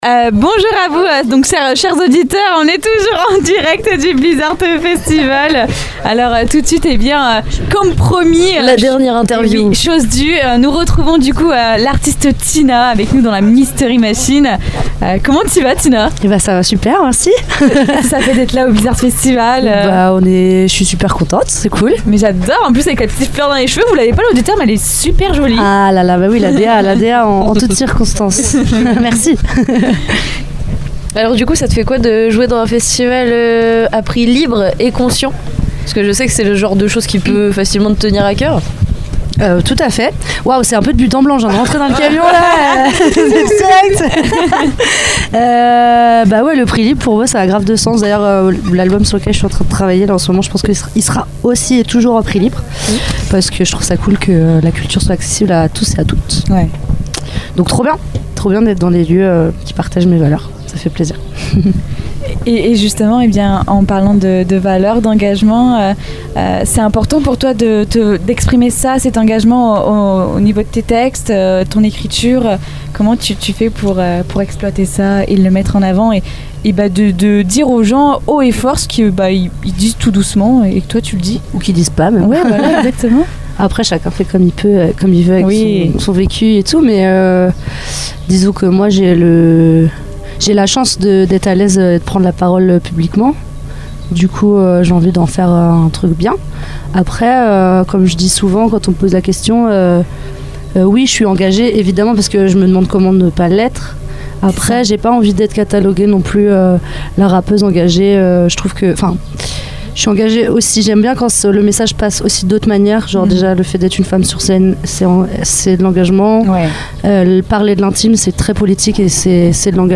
À l’issue de sa prestation, elle nous a rejoint dans la Mystery Machine (studio radio aménagé dans une camionnette) pour une interview exclusive diffusée en direct sur Radio Pulse et Radio Coup de Foudre.